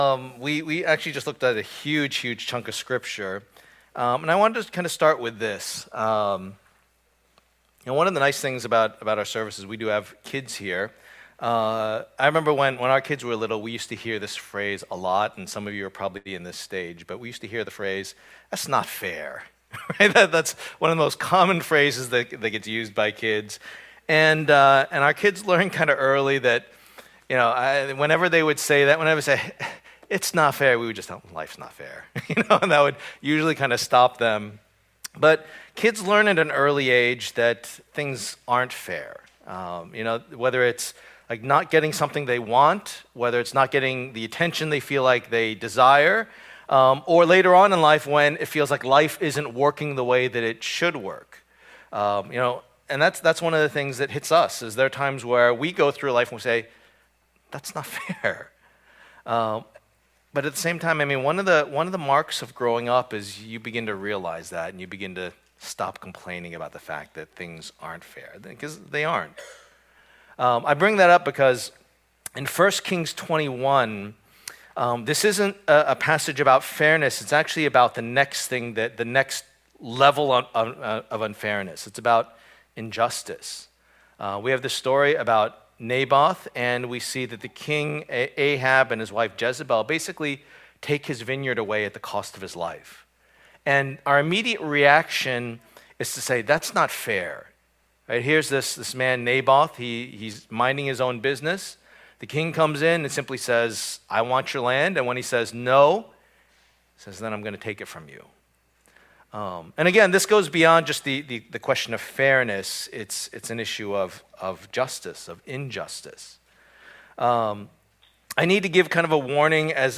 Fighting Against the Idols Passage: 1 Kings 21:1-29 Service Type: Lord's Day %todo_render% « I